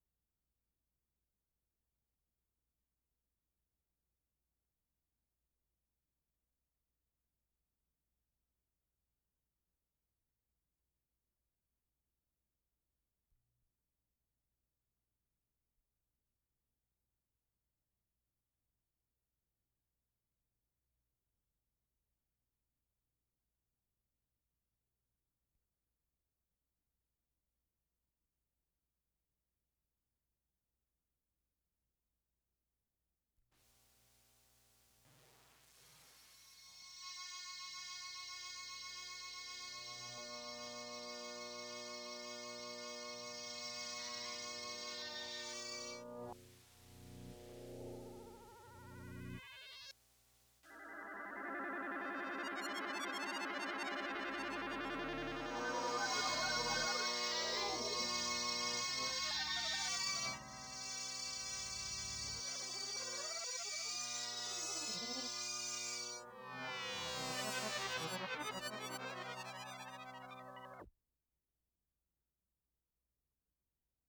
Summer 1979, 4ch pieces
Experimental Music Studio